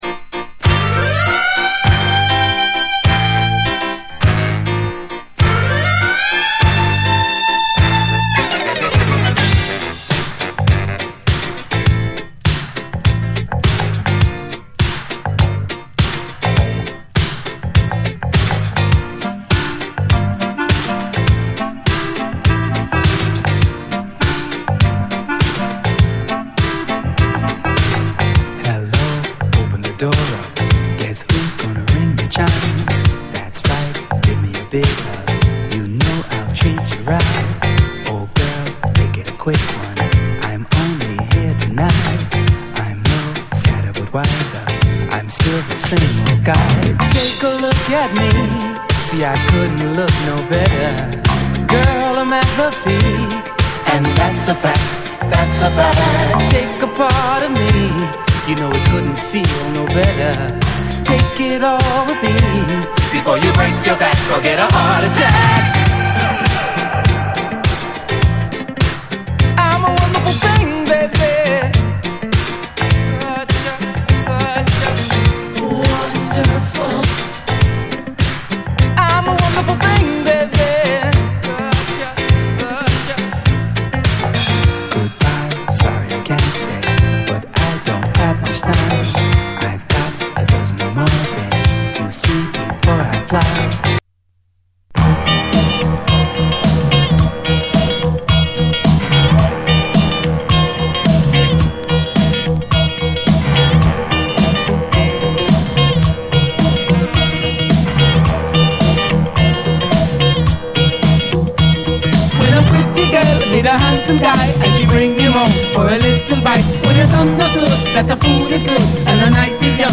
SOUL, DISCO, FUNK-A-LATINA
イントロから俄然テンション上がる、ファンカラティーナ〜ディスコ・クラシック！